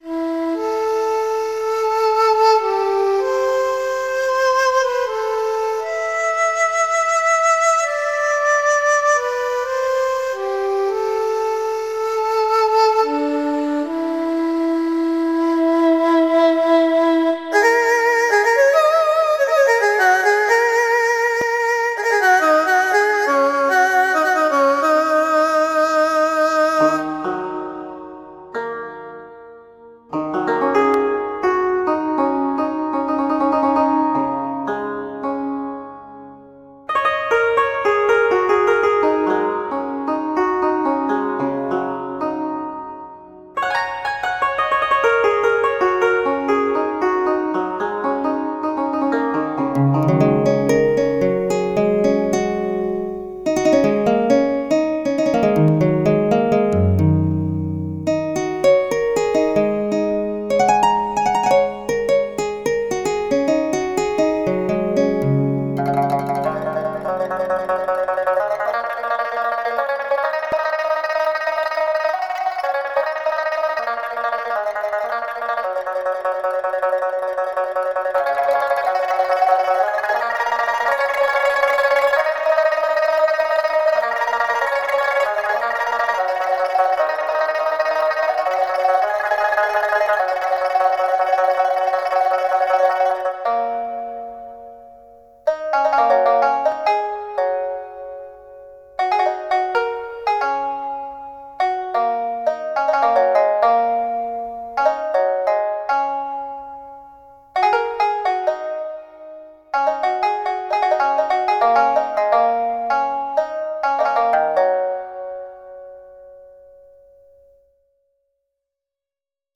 Oriental Sounds
MP3 Demo